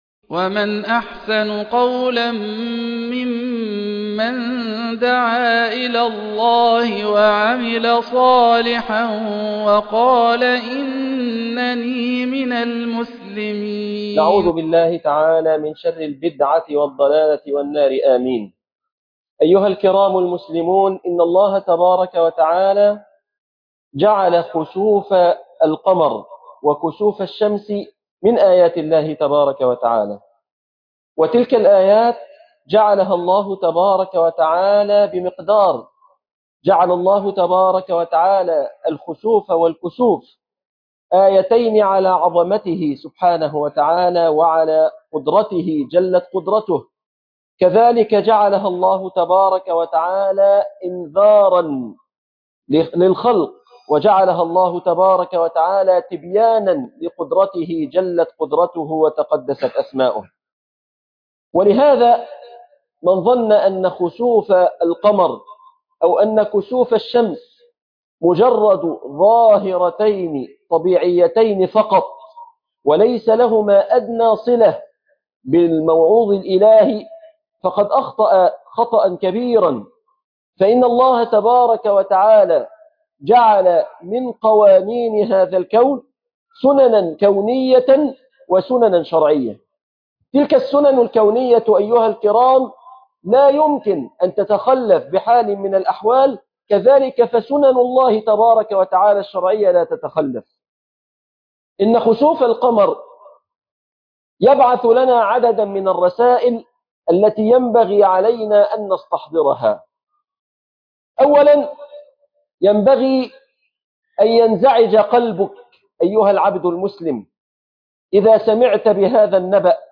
خسوف القمر ... ورسائل للبشر .. خطب الجمعة